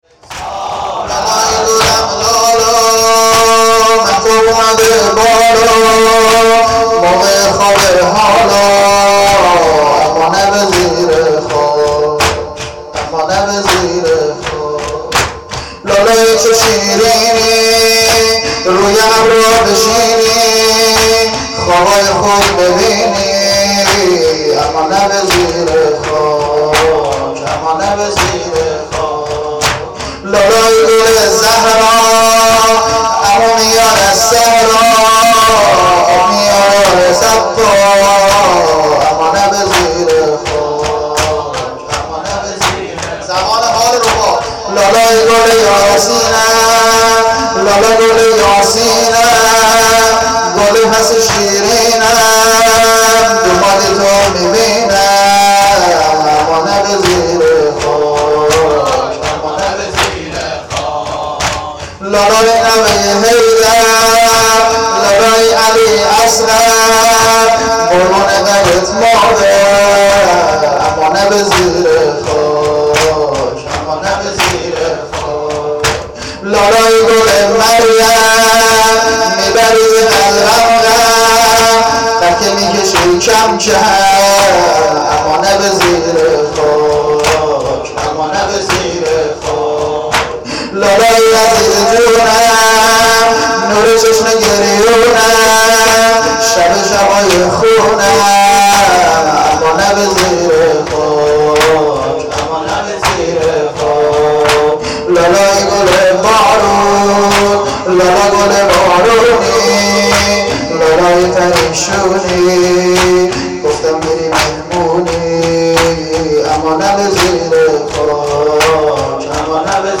• مراسم سینه زنی شب هفتم محرم هیئت روضه الحسین